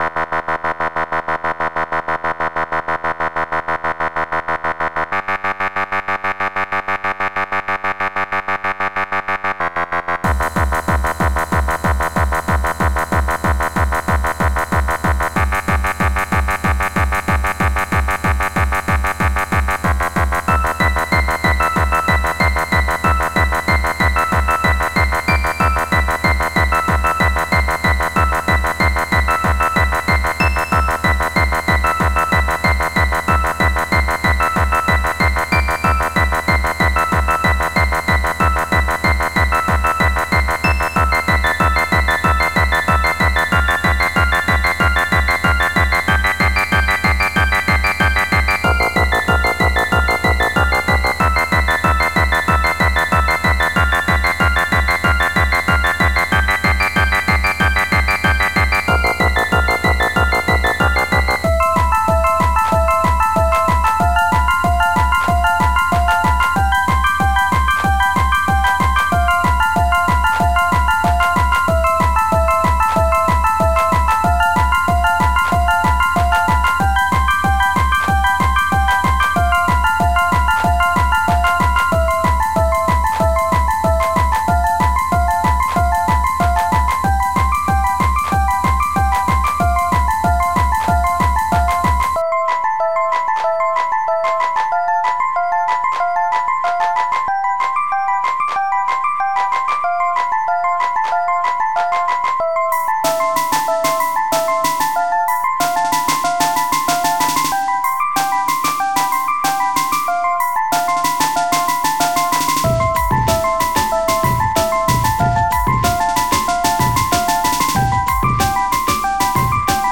Extended Module
-Trippin' on haRiBo- Type xm (FastTracker 2 v1.04)